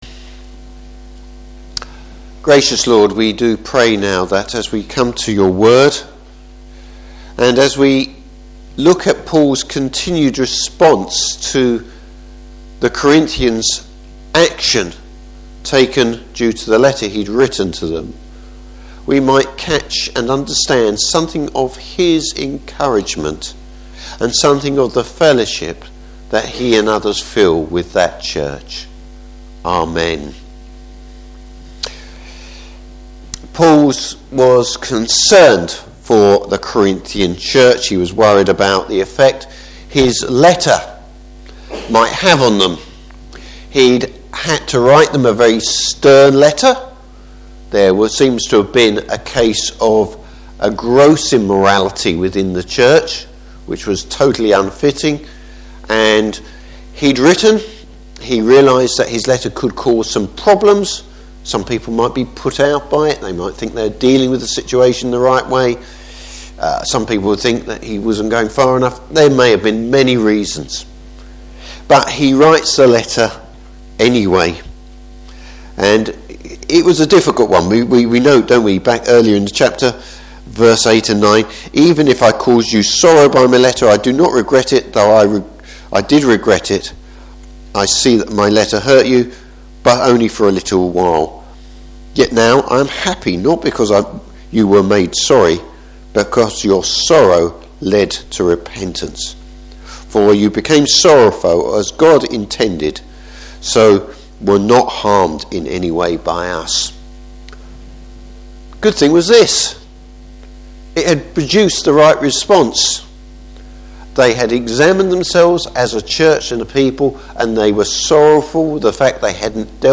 Service Type: Morning Service Paul’s upbeat assessment of the Corinthian Church.